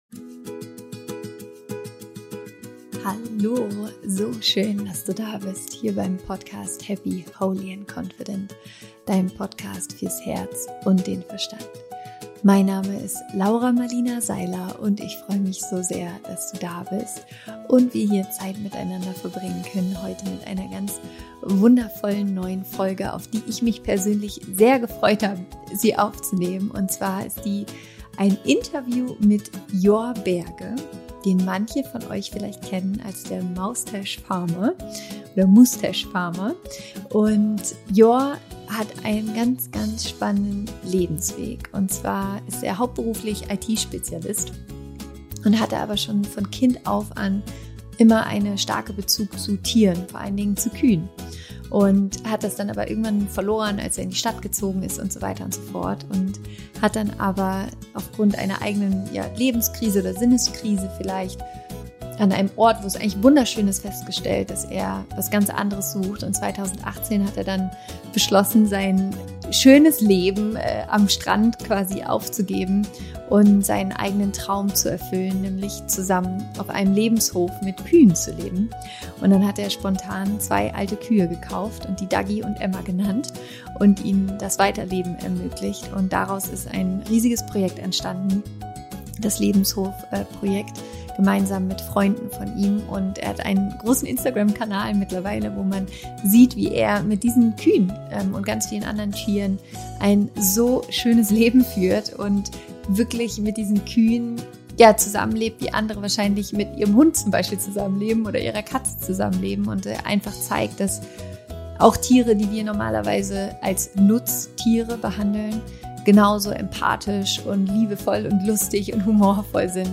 Wo du das Glück findest - Interview Special